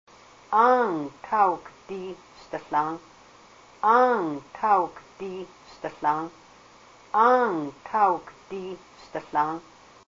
The following are a collection of phrases recorded with native speakers of Haida during a trip to Ketchikan and Hydaburg, Alaska, in March, 2003.
a native speaker of the Kasaan dialect of Alaskan Haida.